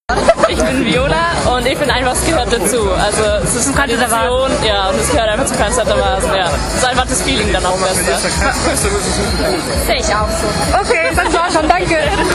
Außerdem habe ich ein paar ganz kleine Interviews mit einigen Dirndlträgerinnen, die mir auf dem Cannstatter Wasen vors Mikrofon gelaufen sind,  geführt.